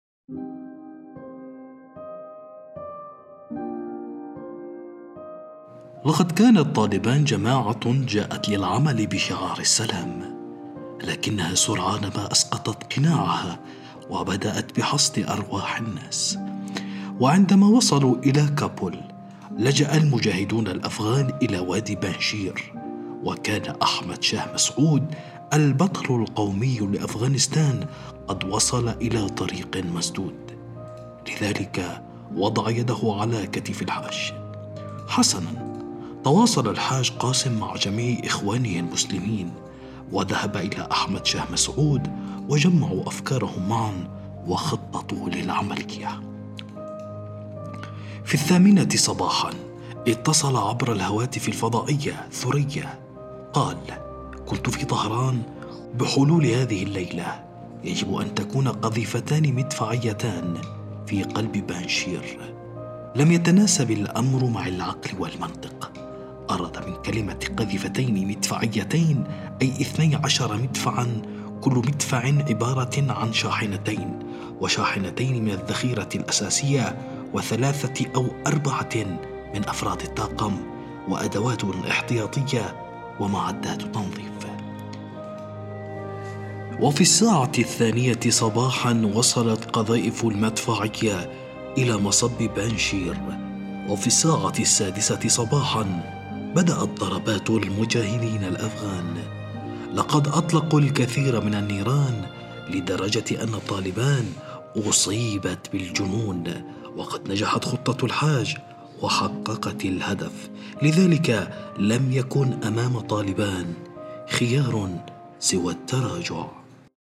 الراوي